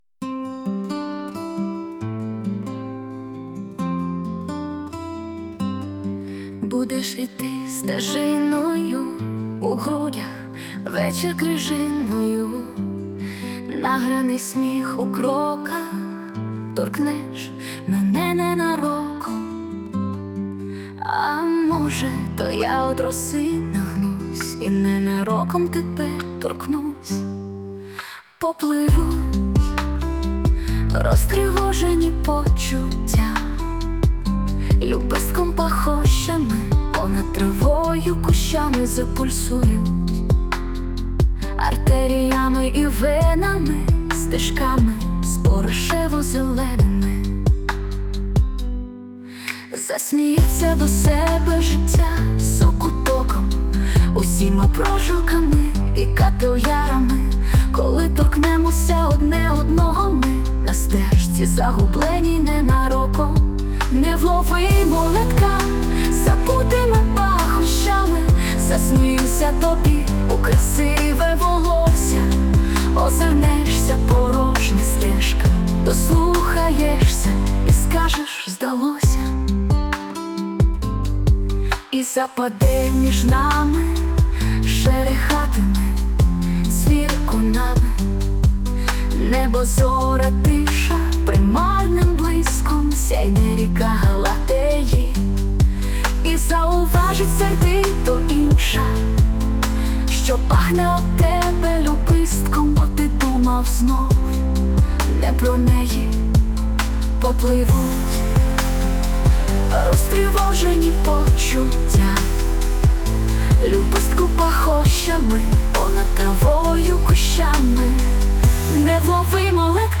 відеорядом (музику і виконання згенерував ШІ)